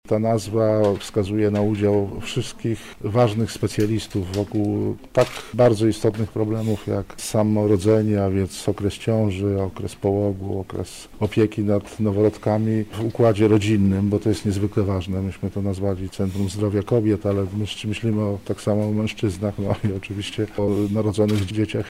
Dzięki temu rodzina może poczuć się bardziej świadomie i bezpieczniej – mówi prof. Wojciech Załuska, rektor Uniwersytetu Medycznego w Lublinie: